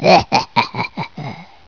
Haha1
HAHA1.WAV